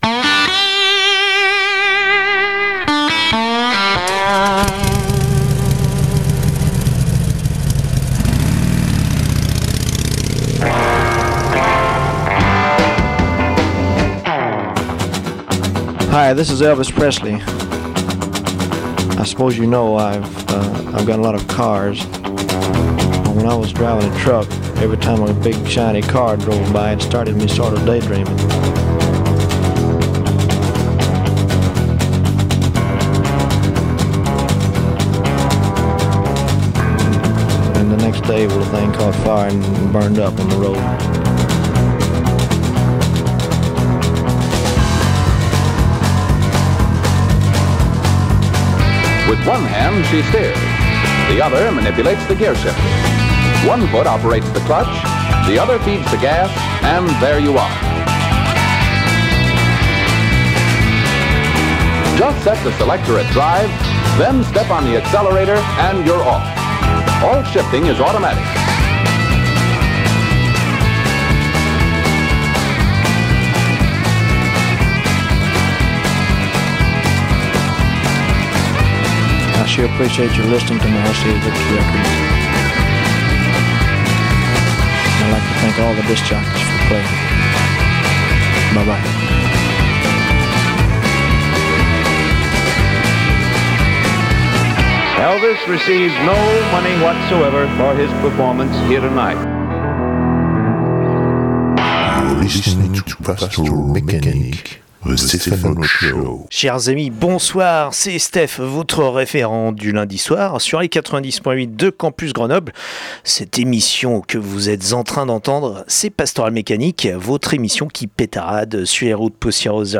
Si l’émission démarre sur ces voix singulières, elle continue sur une symphonie instrumentale twang pleine de réverb’.